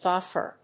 ディー‐エフディーアール